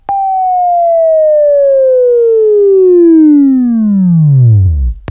Sweeping Training
Buzz